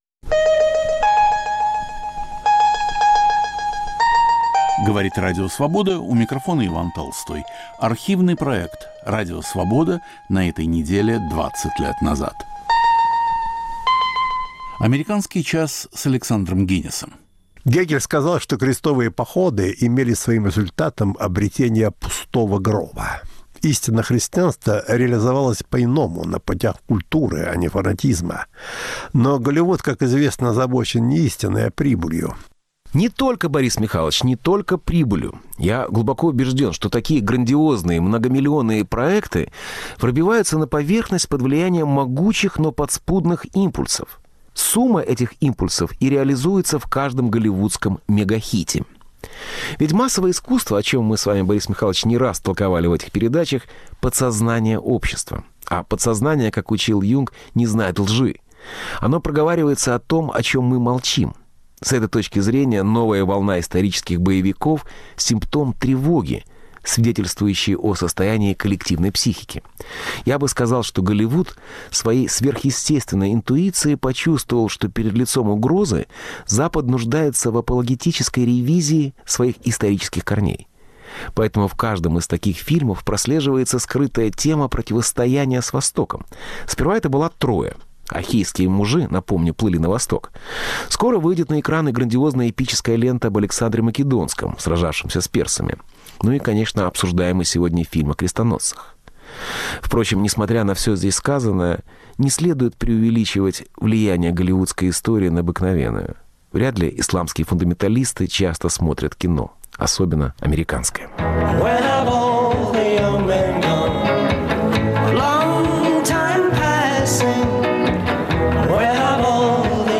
Интервью Энди Уорхола.